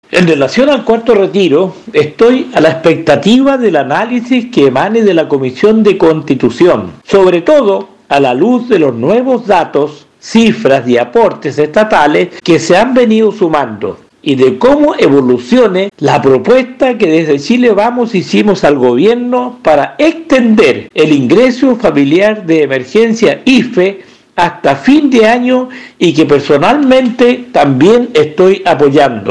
Desde el oficialismo, el diputado Bernardo Berger afirmó que está apoyando el proyecto impulsado por el sector para ampliar el IFE Universal hasta diciembre.